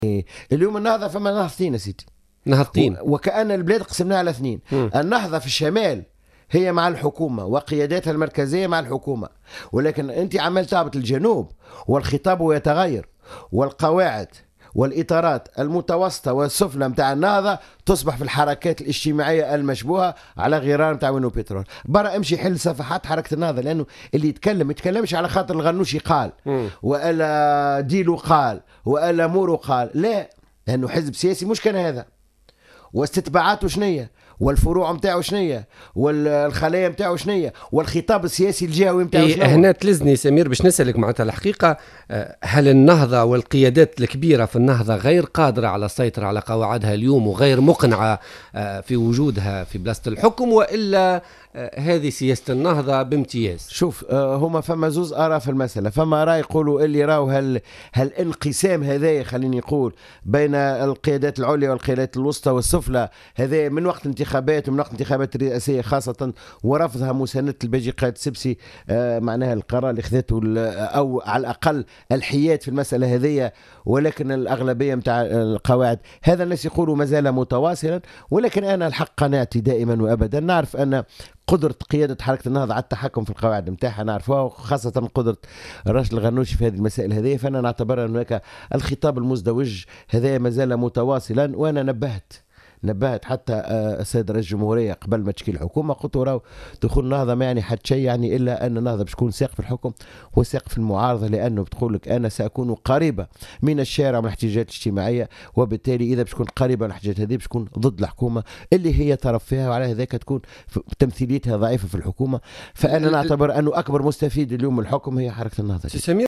Le secrétaire général du parti Al Massar Samir Bettaieb, invité de Politica du mardi 16 juin 2015, a estimé que la Tunisie passe par des situations économique, sociale et sécuritaire difficiles.